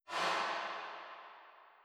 Snare (Window).wav